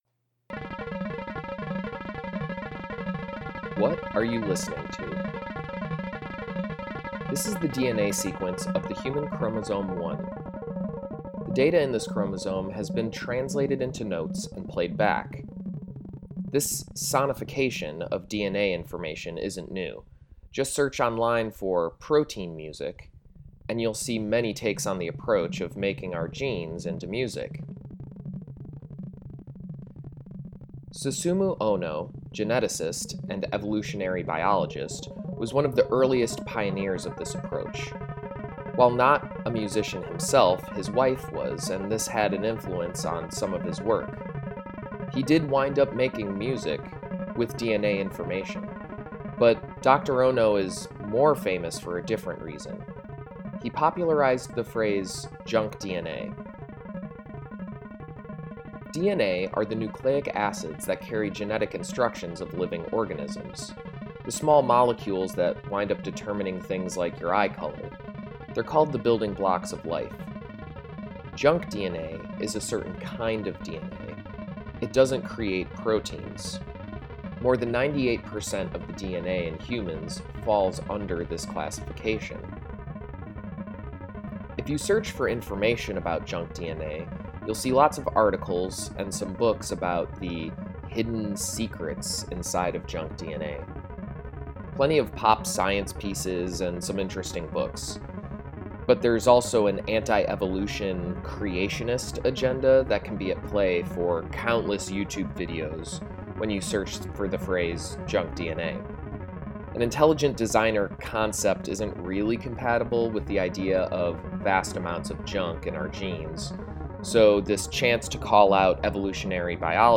I’m doing my best impression of a trendy podcast in order to get people excited about a potential way of sonifying data. In my research, I’ve seen some direct links between my topic and sonification (Dr.Ohno practiced this himself), but also some metaphorical ones.